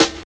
60 SNARE 3.wav